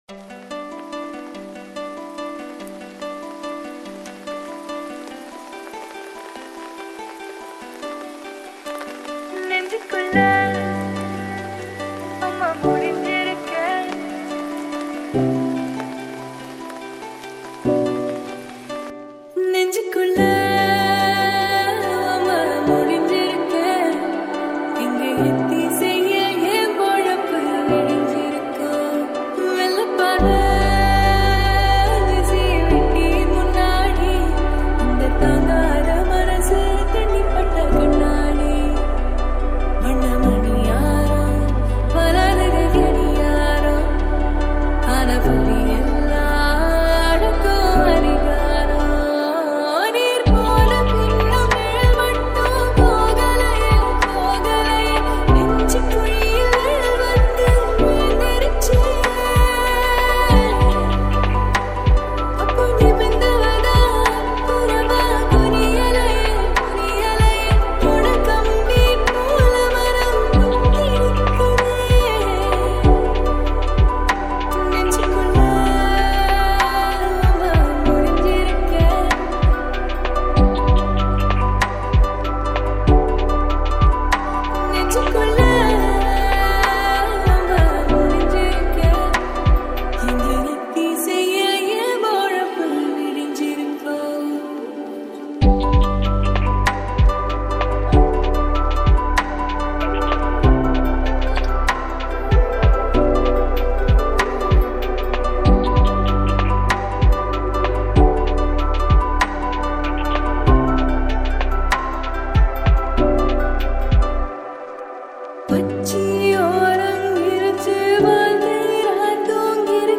All TAMIL LOFI REMIX